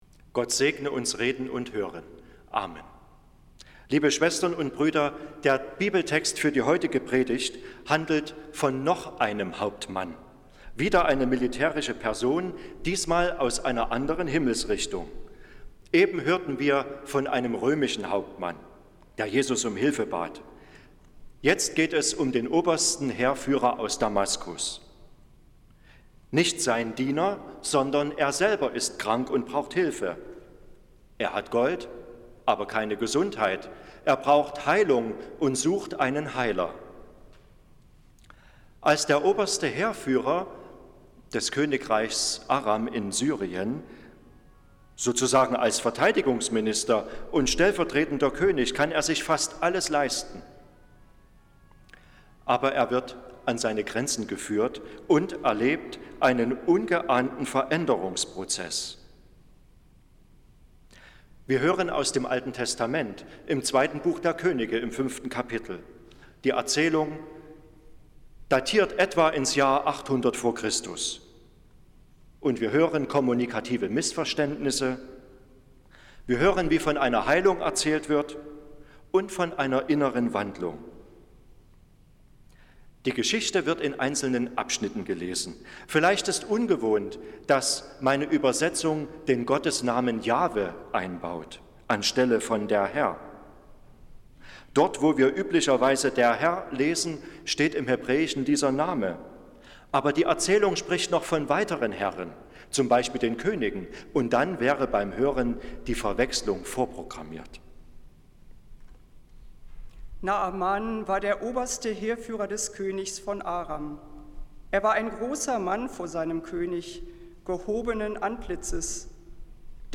Predigt des Gottesdienstes aus der Zionskirche vom Sonntag, den 21. Januar 2024